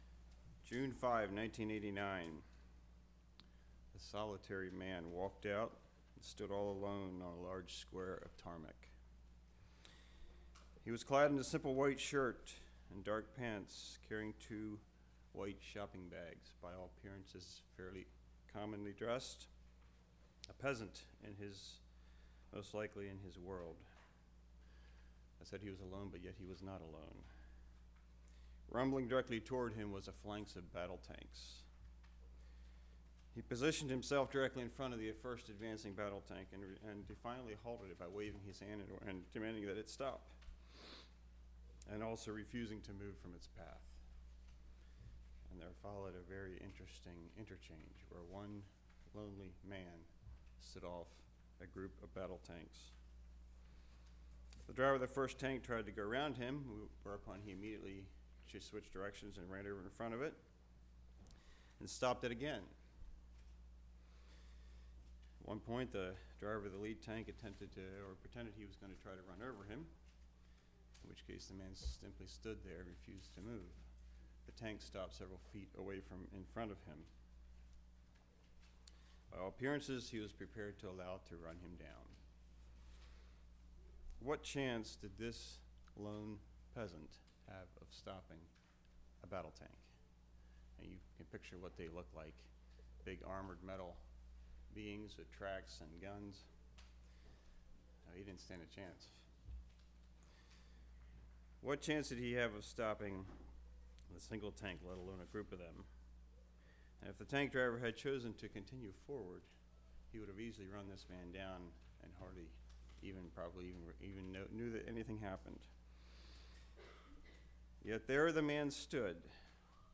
Play Now Download to Device The Gift of Suffering Congregation: Leola Speaker